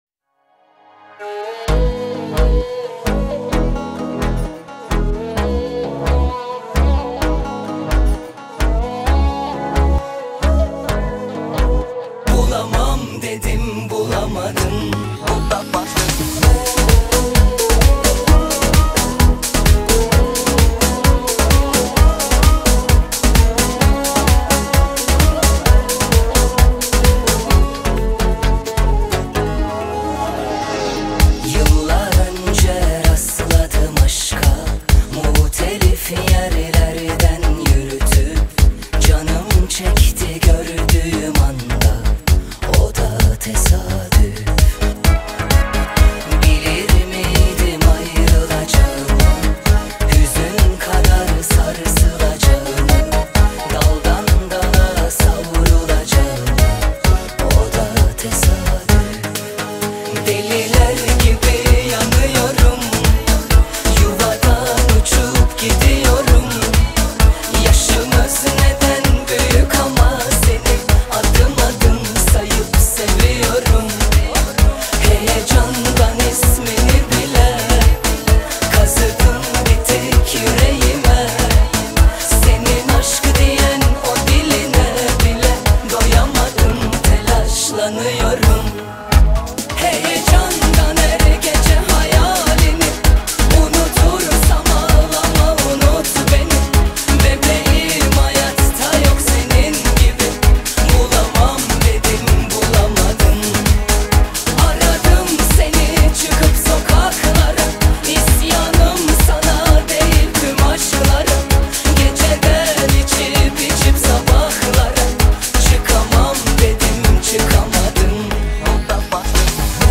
один из самых популярных турецких поп-исполнителей